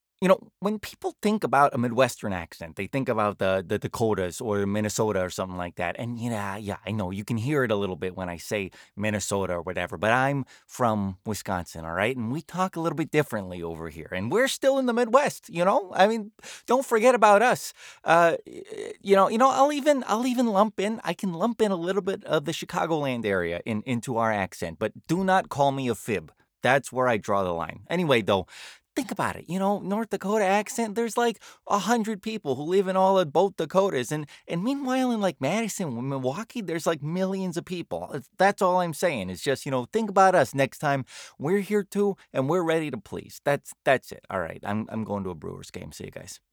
midwestern us | natural
Wisconsin.mp3